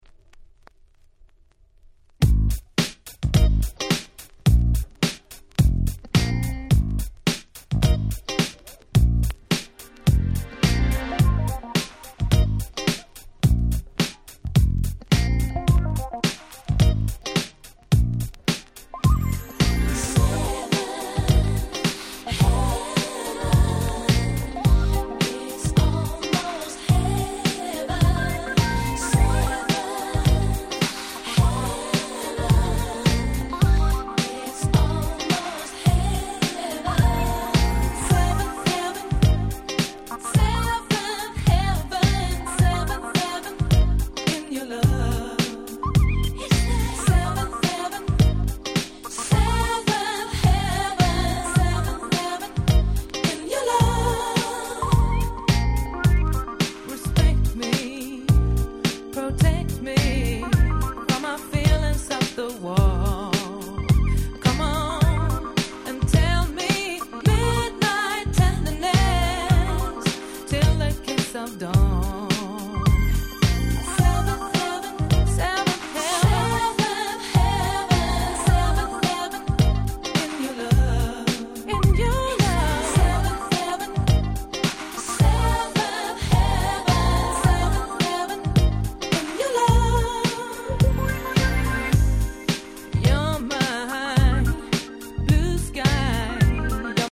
97' Nice Cover UK R&B !!